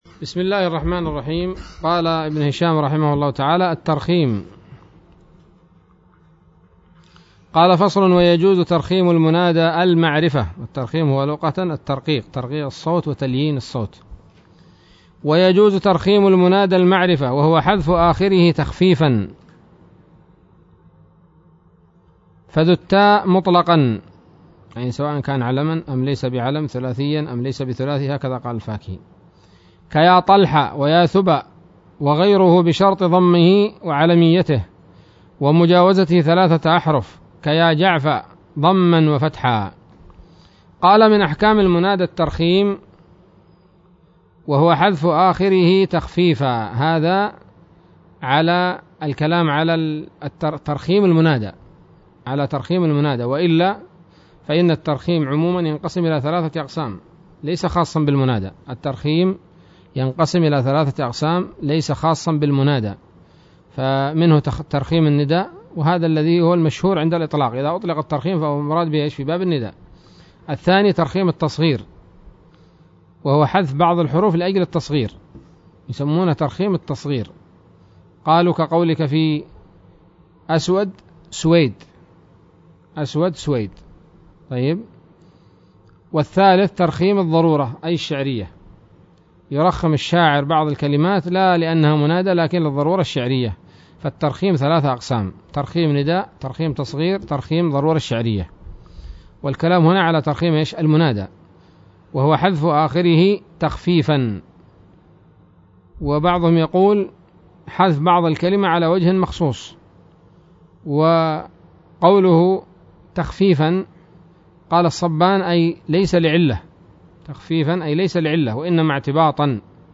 الدرس التاسع والثمانون من شرح قطر الندى وبل الصدى